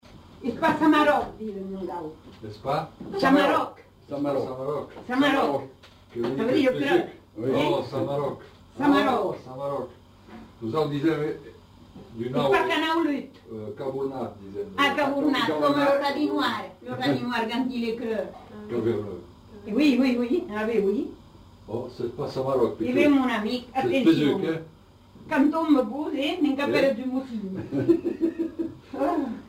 Lieu : Tonneins
Genre : forme brève
Effectif : 1
Type de voix : voix de femme
Production du son : parlé
Classification : locution populaire